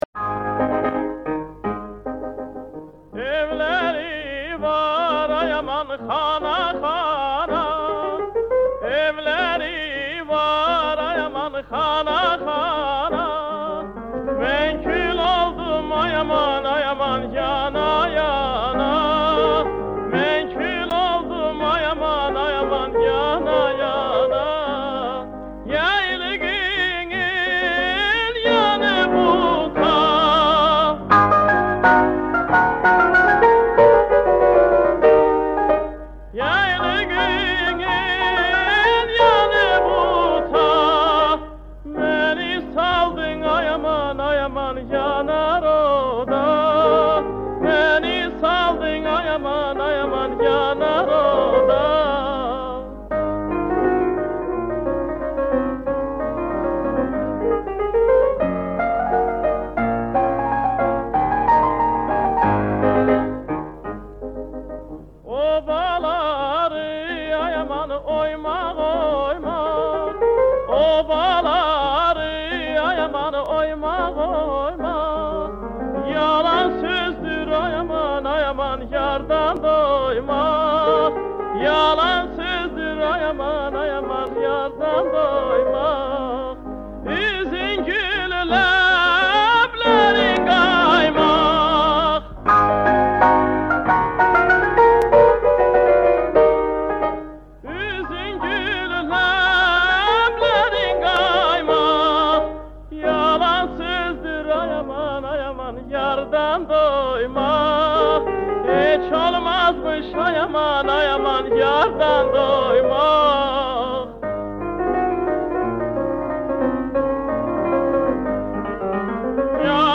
Azeri music